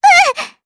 Estelle-Vox_Damage_jp_1.wav